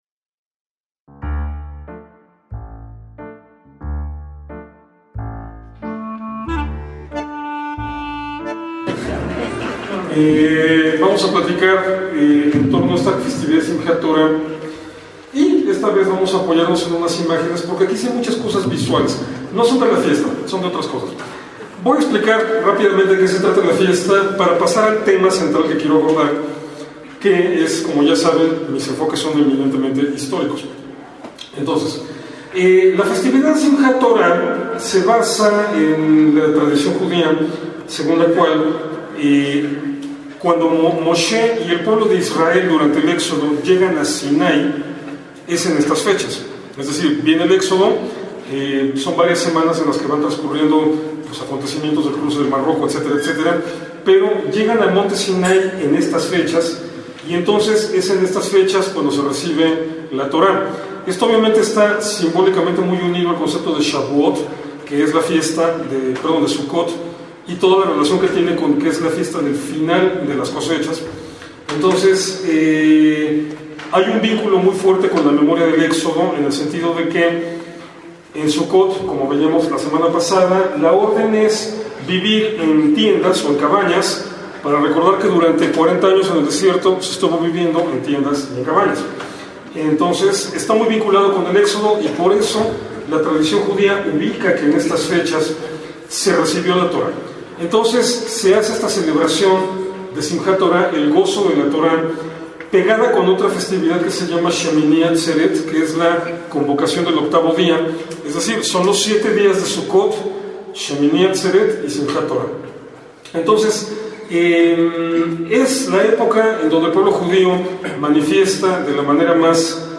Sinagoga Justo Sierra, México
ACTOS EN DIRECTO - Simjat Torá se celebra al concluir la festividad de Sucot,, señalando el final de la lectura en la sinagoga del Pentateuco y se recomienza. En hebreo significa regocijo con la Torá, y se celebra desde los tiempos talmúdicos, cuando existía todavía la costumbre babilónica de completar en un año la lectura de la Torá.